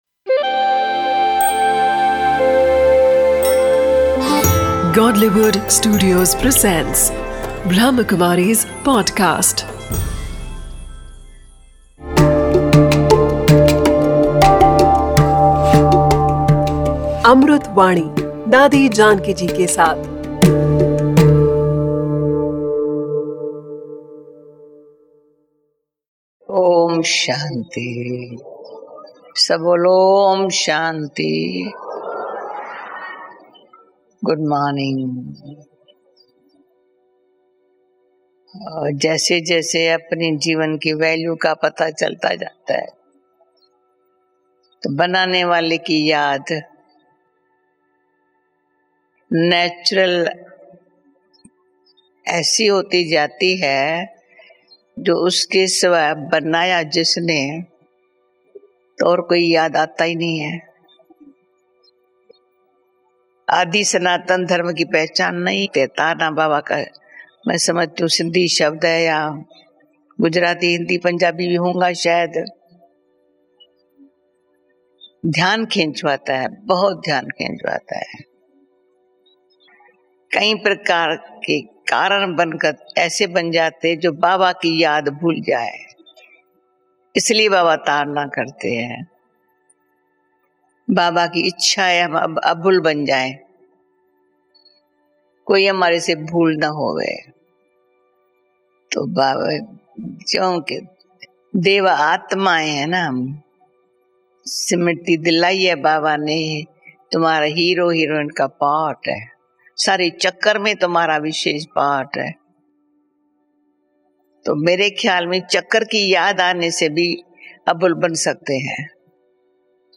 a collection of invaluable speeches